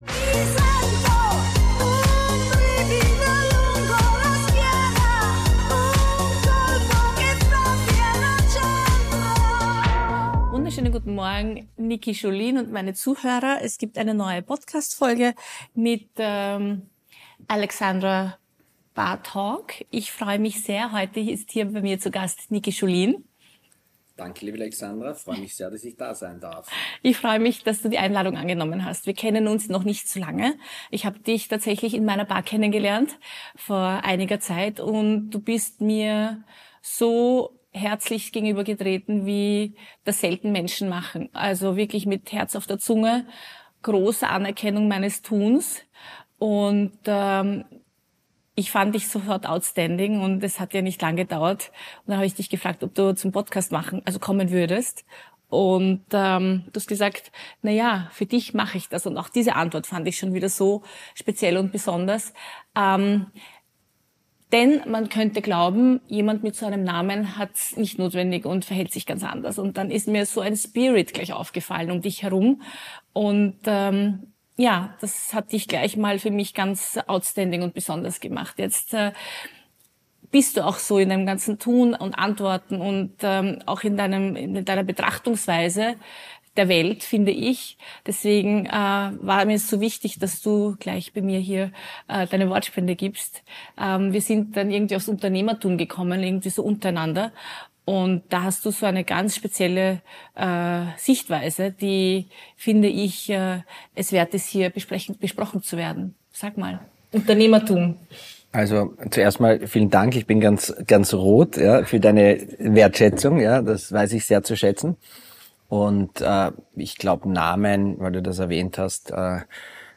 Zwischen Drink und Dialog entstehen Gespräche, die Tiefe haben - mal leise, mal laut, aber es immer wird Tacheles geredet.